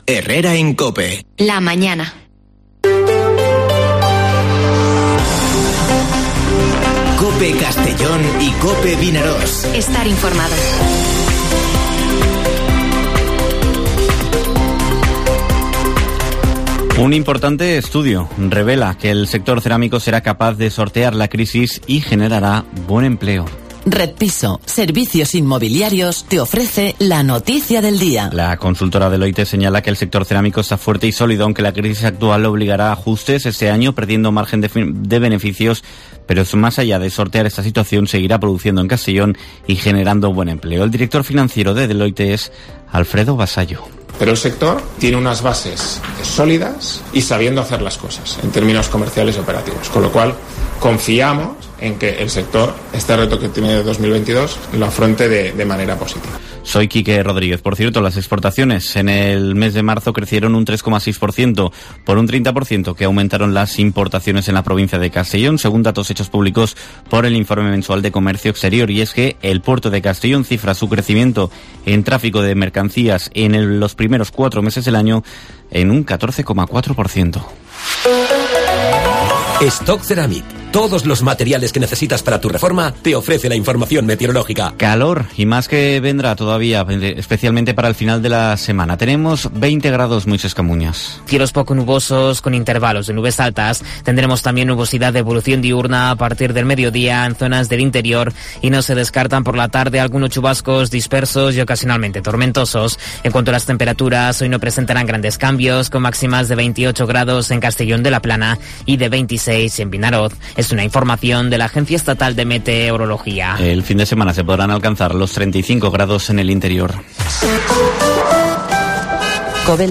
Informativo Herrera en COPE en la provincia de Castellón (18/05/2022)